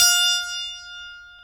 GTR EL-AC106.wav